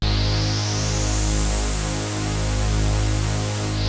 KORG G#2 3.wav